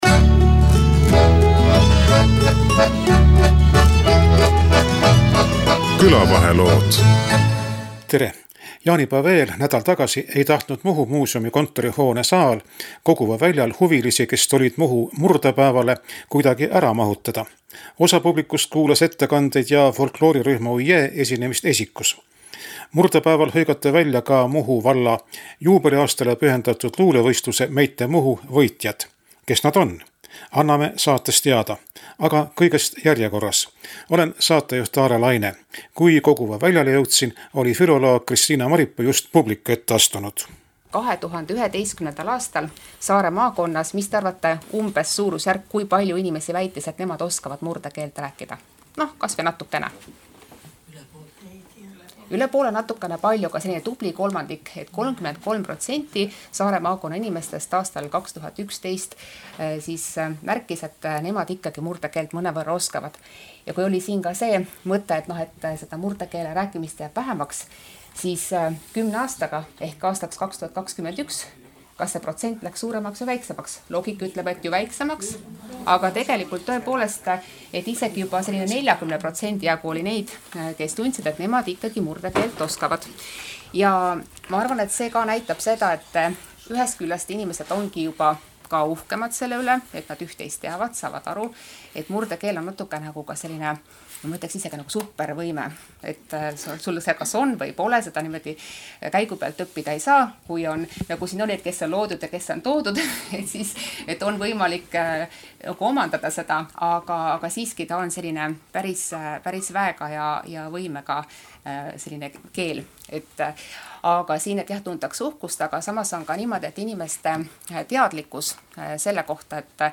Nädal tagasi Koguva Väljal toimunud Muhu murdepäeval kuulas saalitäis huvilisi